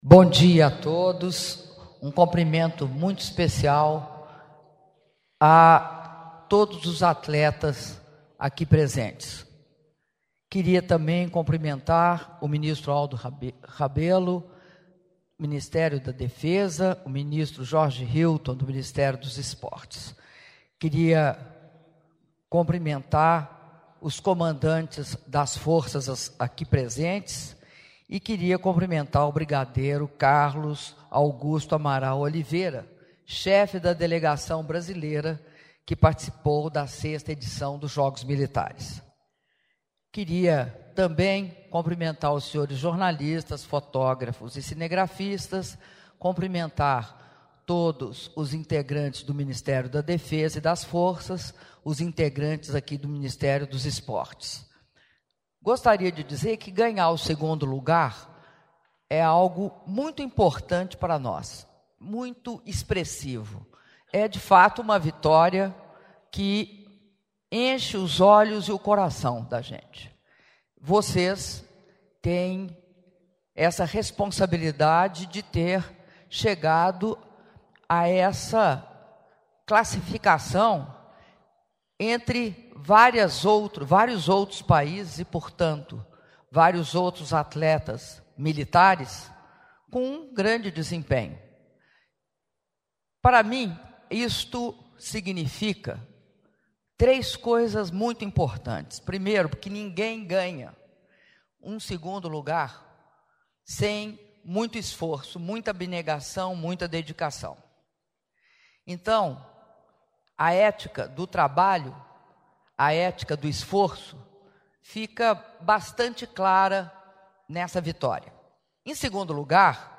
Áudio do discurso da Presidenta da República, Dilma Rousseff, durante o encontro com os atletas militares medalhistas da 6ª edição dos Jogos Mundiais Militares na Coreia do Sul (05min36s) — Biblioteca